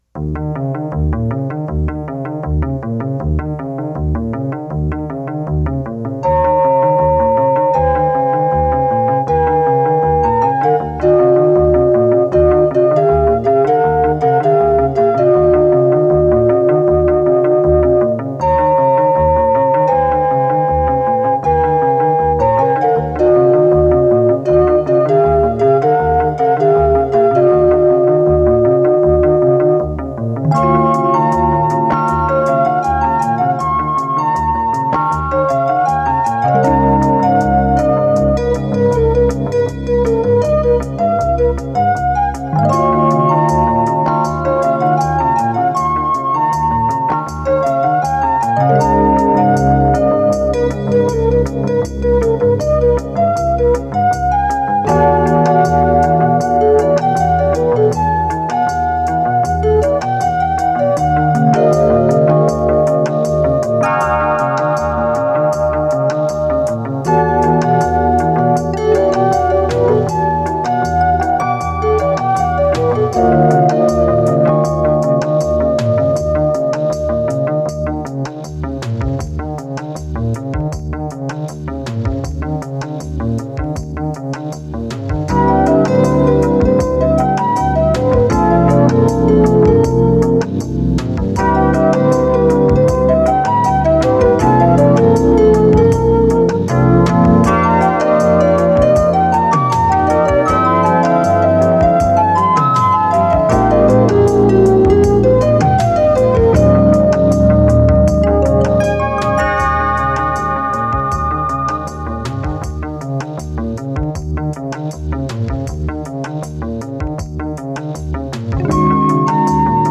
lead synthesizer.
synthesizer accompaniment, synth-bass and drum sequencing.
electric guitar and sound effects.